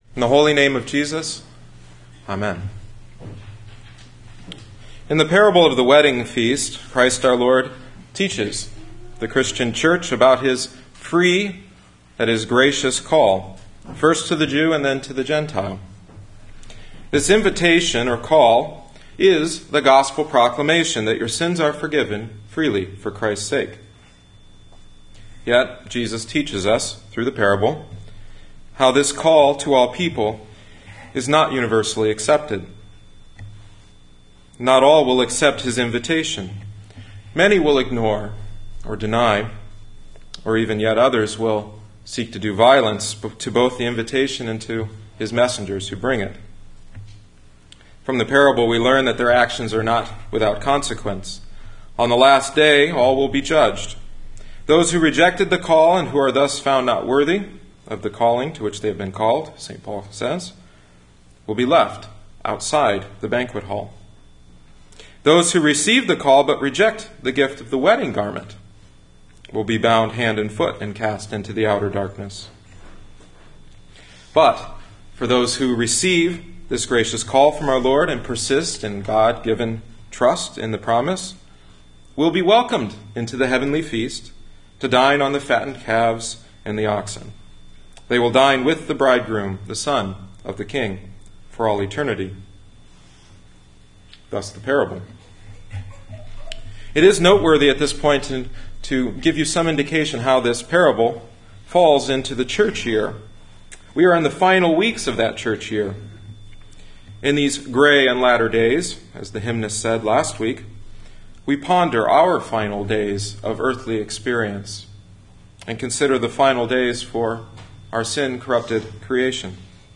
October 2010 Twentieth Sunday after Trinity Matthew 22:1-14 “The Banquet of Grace”